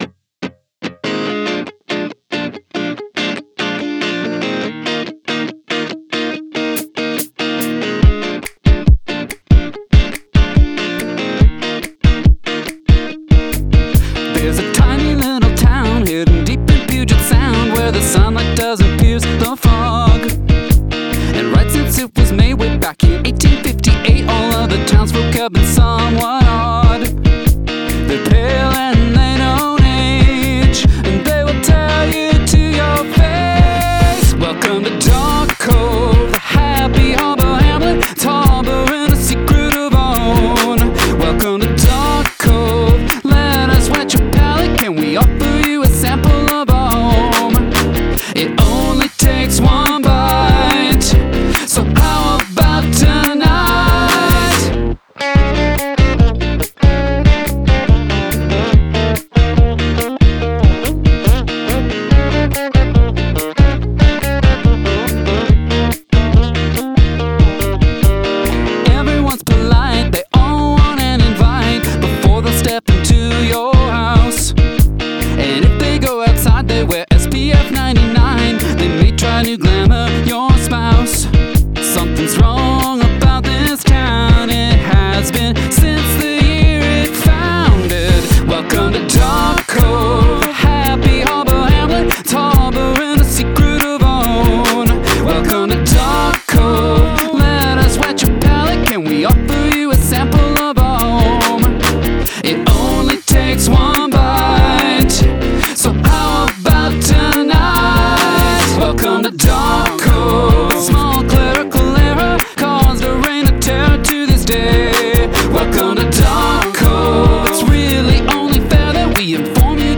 Make use of handclaps and snaps
The sly solo is sweet, appropriately simple.